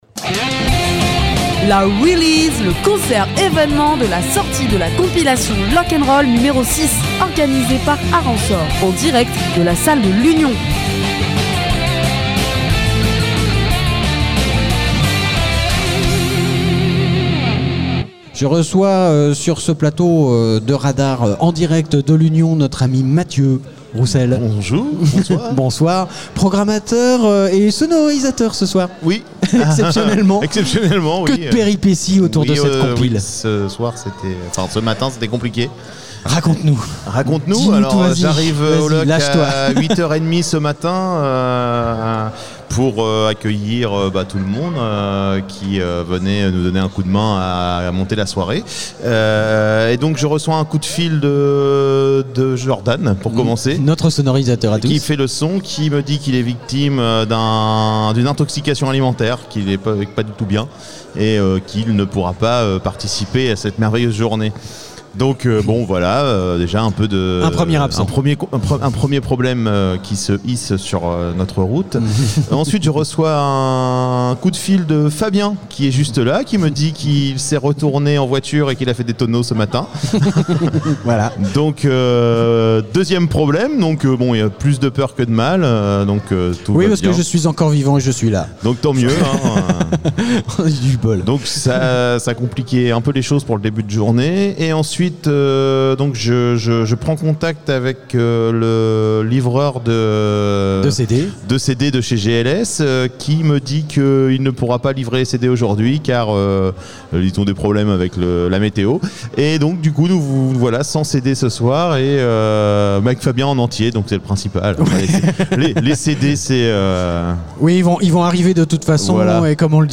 Art en sort LIVE ! interviews événement Interview festival Art en sort concert Itv live festivals artensort concerts release